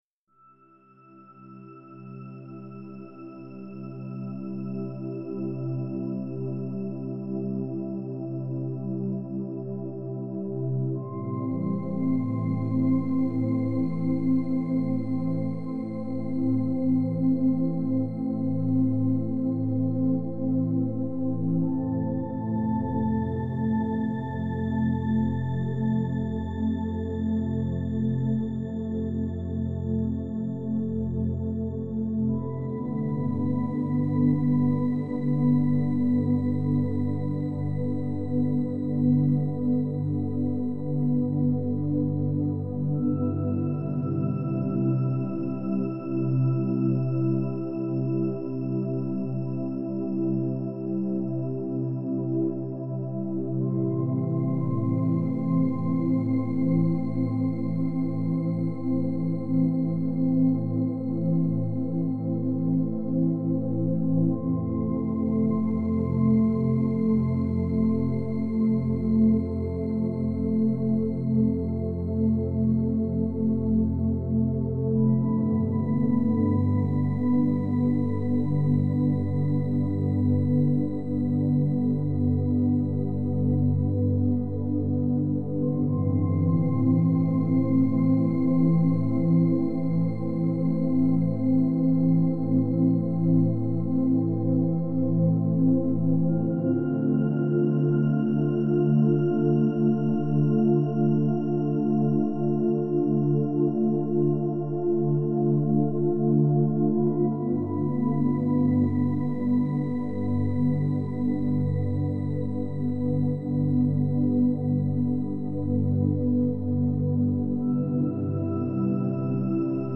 это атмосферная композиция в жанре неоклассической музыки